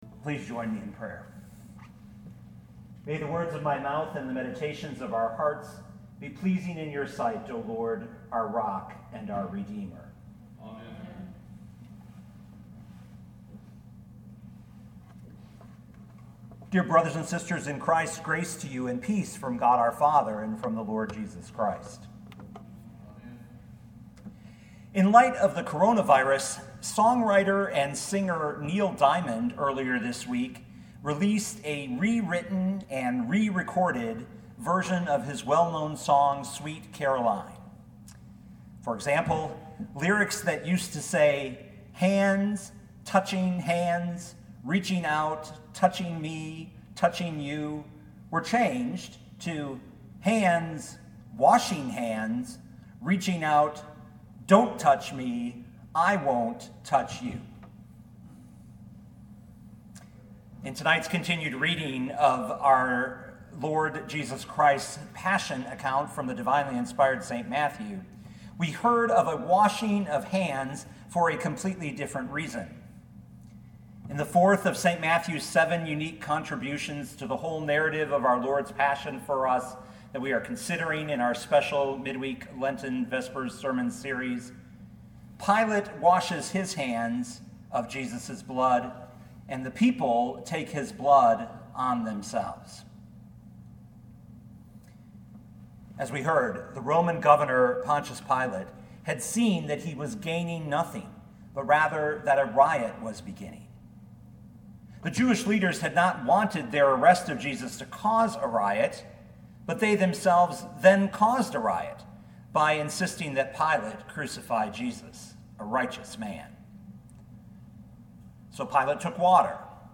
2020 Matthew 27:24-25 Listen to the sermon with the player below, or, download the audio.